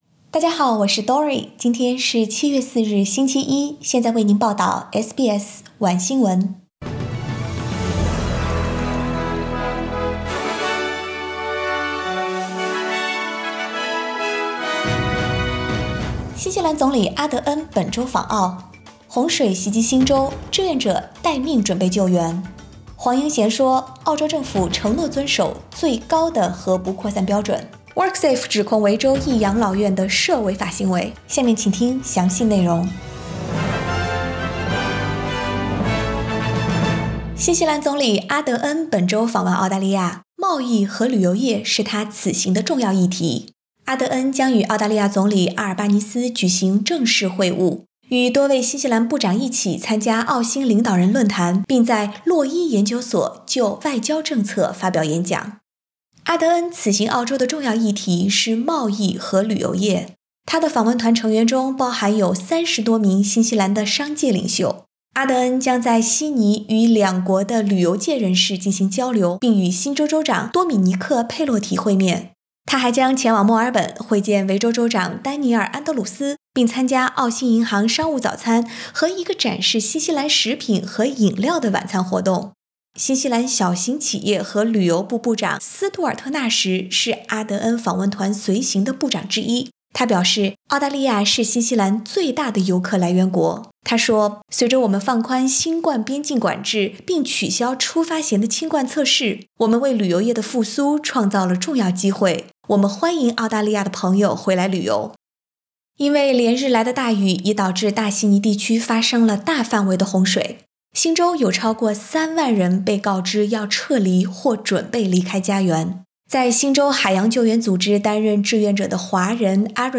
SBS晚新聞（2022年7月4日）